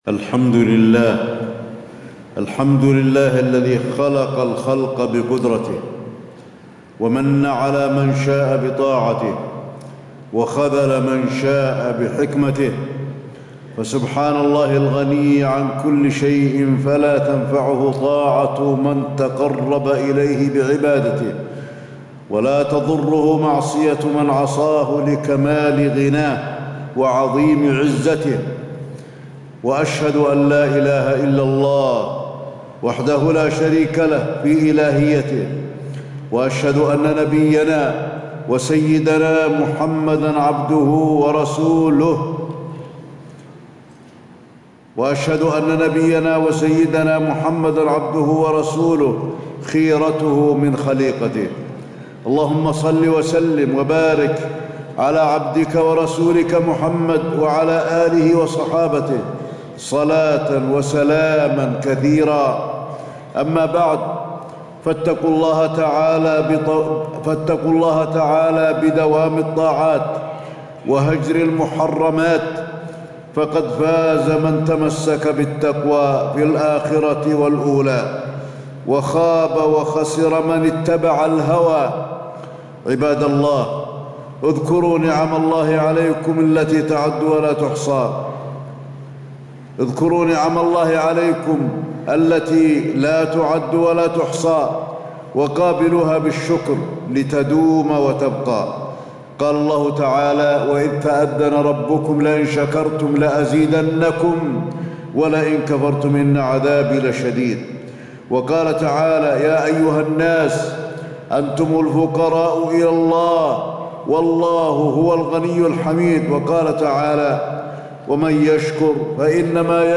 تاريخ النشر ١ شوال ١٤٣٦ هـ المكان: المسجد النبوي الشيخ: فضيلة الشيخ د. علي بن عبدالرحمن الحذيفي فضيلة الشيخ د. علي بن عبدالرحمن الحذيفي دوام الطاعات بعد رمضان